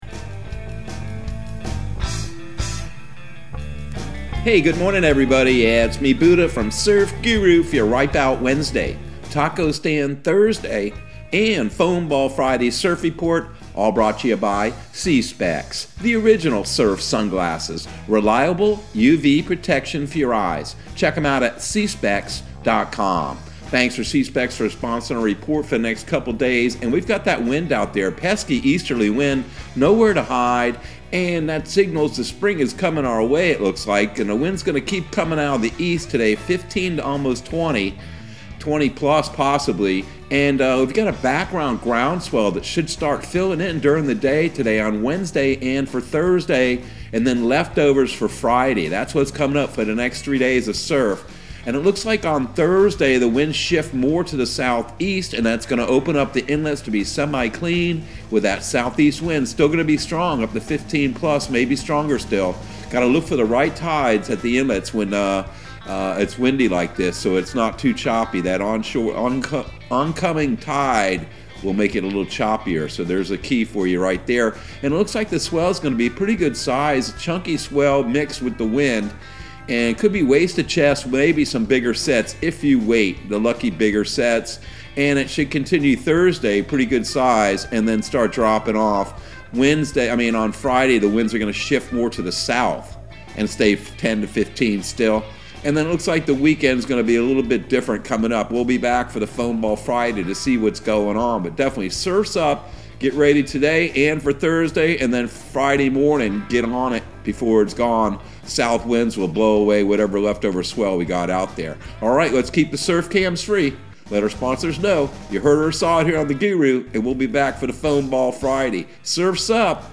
Surf Guru Surf Report and Forecast 02/16/2022 Audio surf report and surf forecast on February 16 for Central Florida and the Southeast.